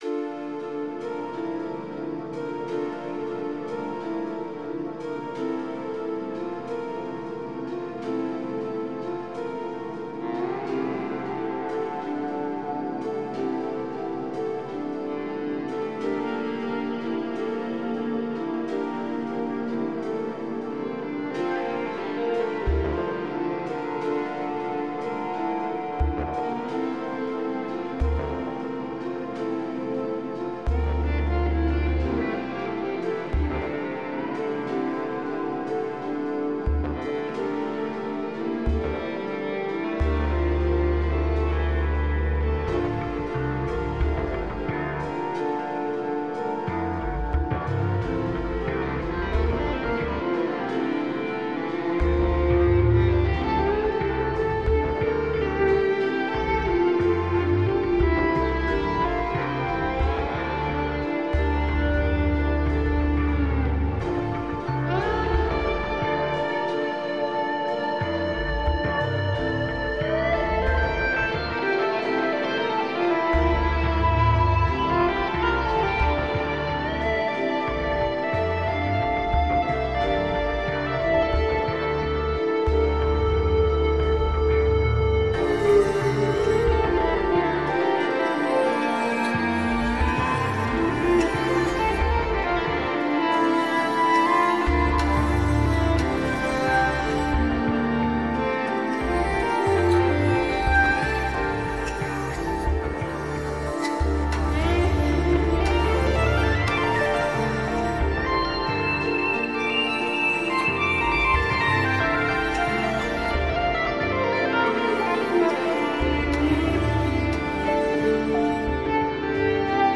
Mitosynth (the most underrated synth ever) Pure Piano Ravenscroft 275 Sugarbytes Factory Nambu iBassist Piano Motifs Animoog Vocal sample from Blocs Wave Other…
Just wanted it to be as spontaneous as possible, so a couple of trial runs and here it is warts and all before my battery dies